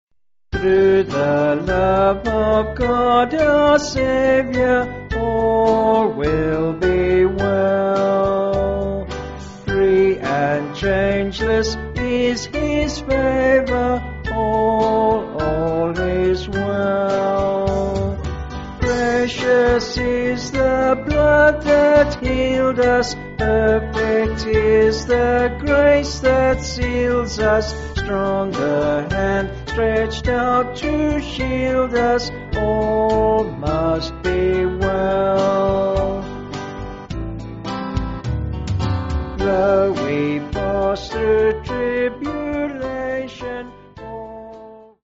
Vocals and Band